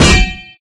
Parry.ogg